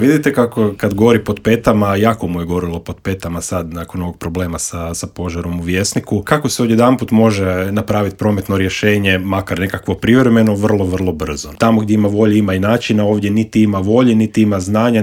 U intervjuu Media servisa ugostili smo jedne od glasnijih kritičara Tomislava Tomaševića i Možemo - nezavisnu zastupnicu u Skupštini Grada Zagreba Dinu Dogan i vijećnika u Vijeću Gradske četvrti Črnomerec Vedrana Jerkovića s kojima smo prošli kroz gradske teme.